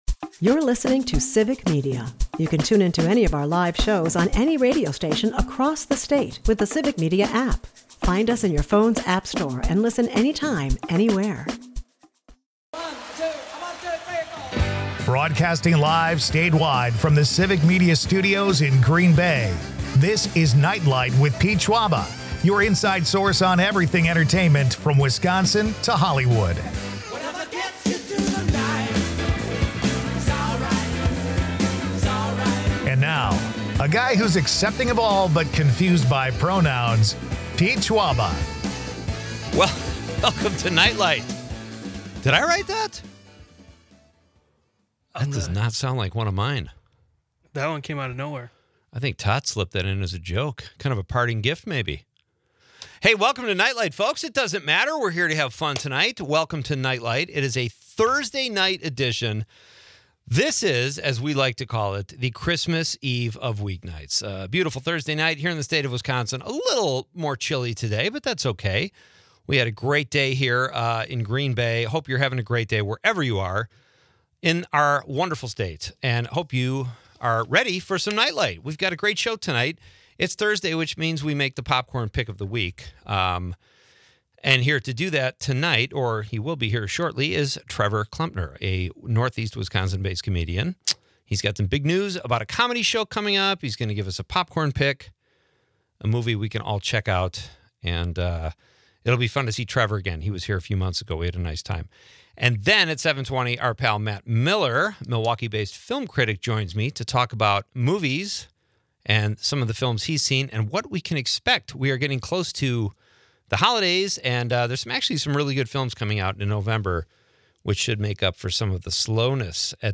Leave the stress of the day behind with entertainment news, comedy and quirky Wisconsin.